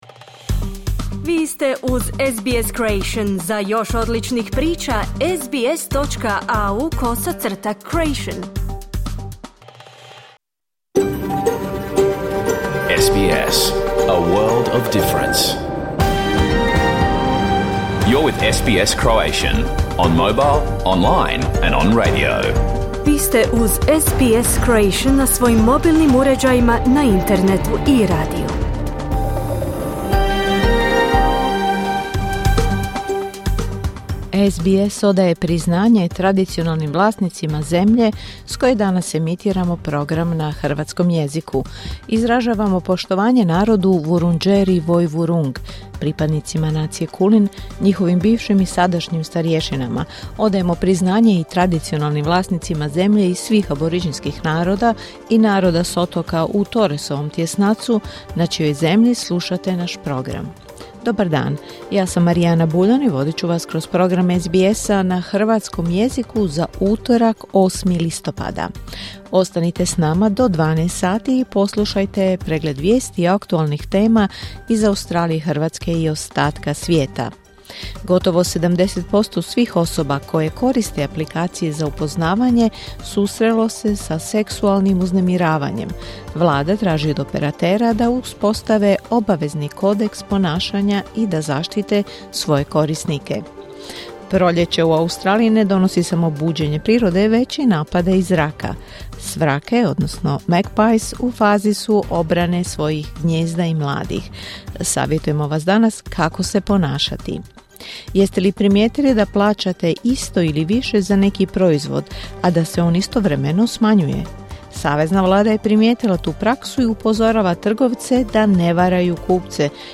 Vijesti i aktualne teme iz Australije, Hrvatske i svijeta. Emitirano uživo na radiju SBS1 u utorak, 8. listopada, u 11 sati po istočnoaustralskom vremenu.